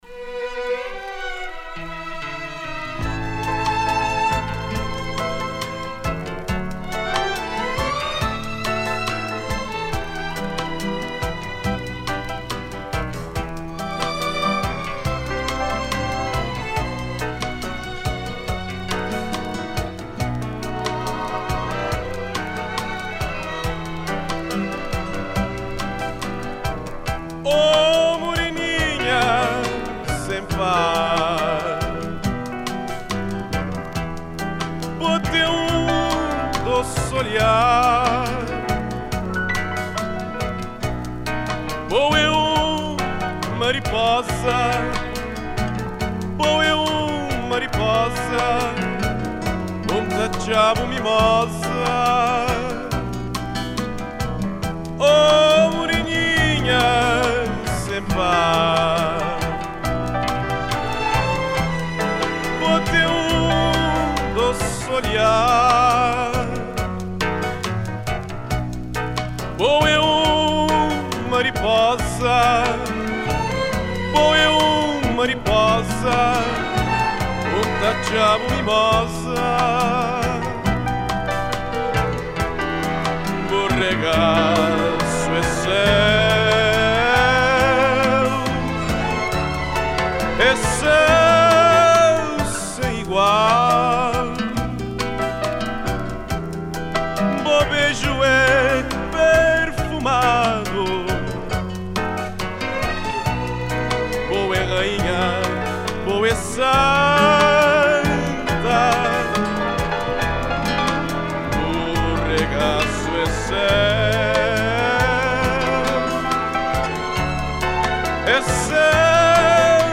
TOM: Em
Category: morna